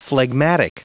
Prononciation du mot phlegmatic en anglais (fichier audio)
Prononciation du mot : phlegmatic
phlegmatic.wav